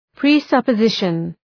Προφορά
{prı,sʌpə’zıʃən}